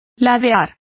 Complete with pronunciation of the translation of tipping.